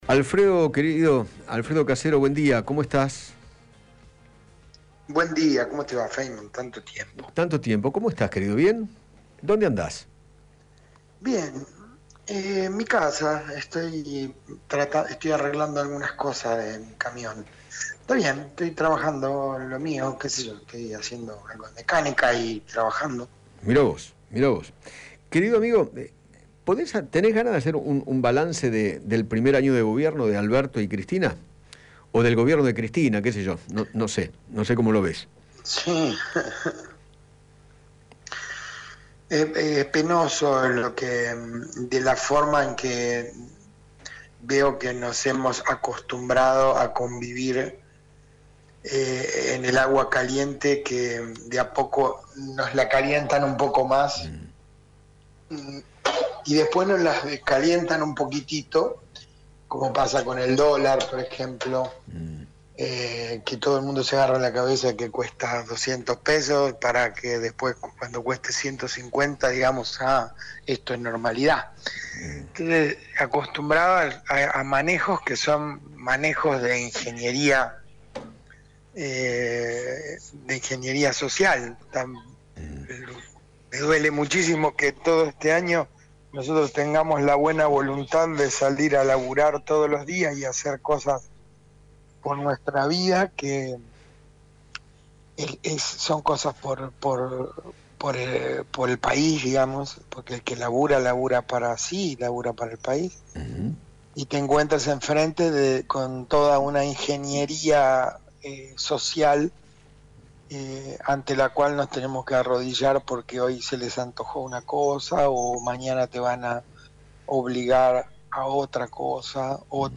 El actor Alfredo Casero dialogó con Eduardo Feinmann acerca del gobierno de Alberto Fernández tras un año de gestión y habló de la grave situación que atraviesa el país.